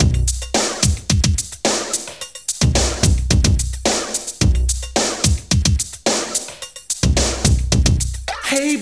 • 109 Bpm Breakbeat B Key.wav
Free breakbeat - kick tuned to the B note. Loudest frequency: 3308Hz
109-bpm-breakbeat-b-key-DAb.wav